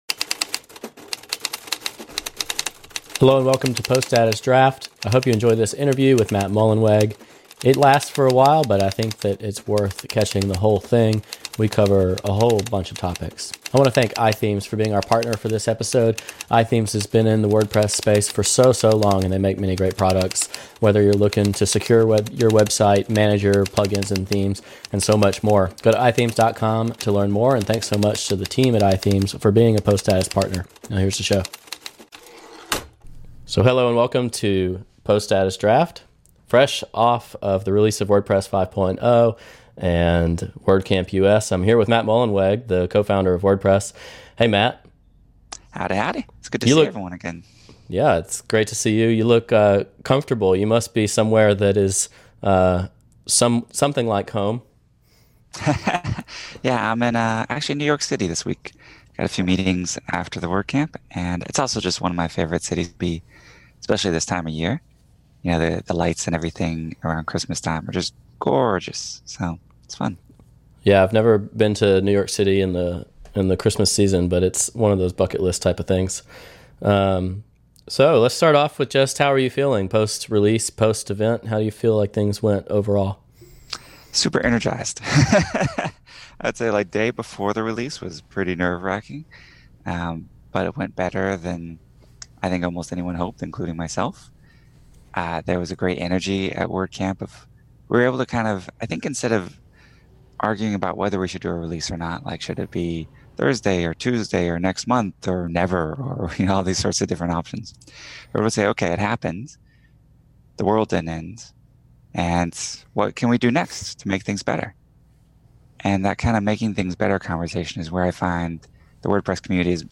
Interview with Matt Mullenweg on Gutenberg, WordPress, and the future